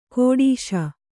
♪ kōḍīśa